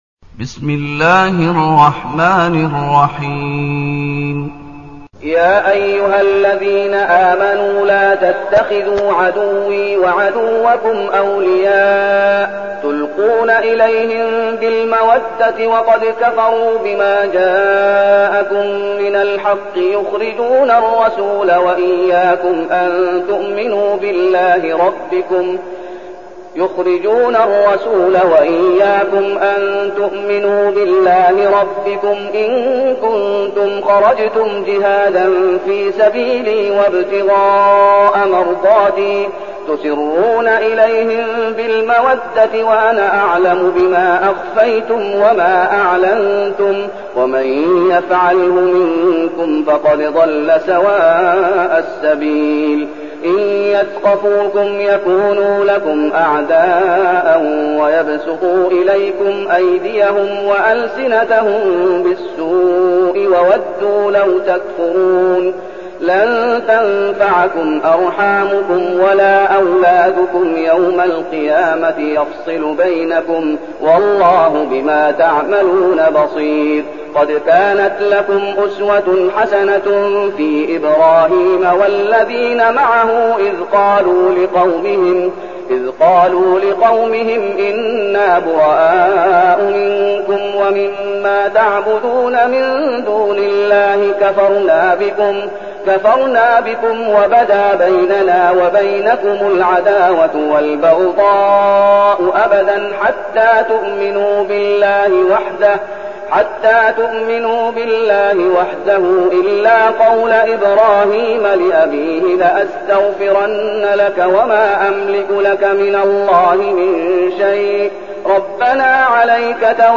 المكان: المسجد النبوي الشيخ: فضيلة الشيخ محمد أيوب فضيلة الشيخ محمد أيوب الممتحنة